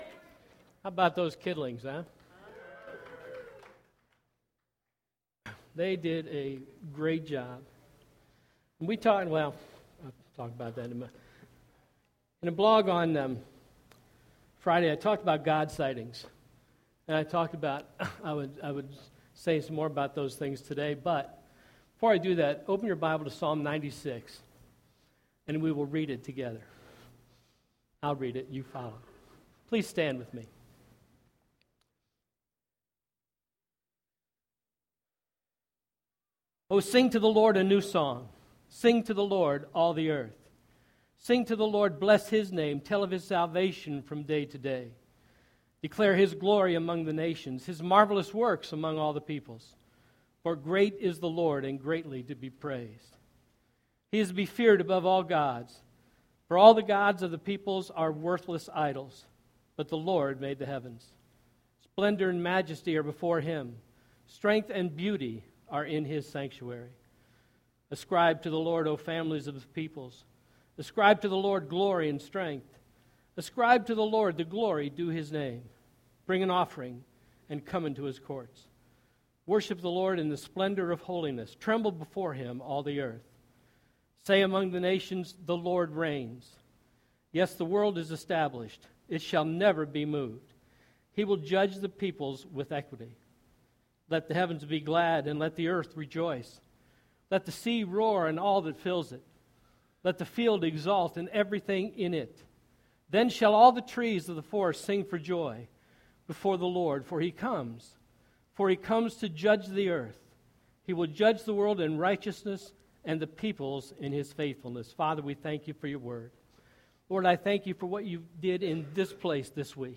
First Baptist Church of Gahanna, OH Sermons